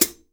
Index of /90_sSampleCDs/AKAI S6000 CD-ROM - Volume 3/Hi-Hat/12INCH_LIGHT_HI_HAT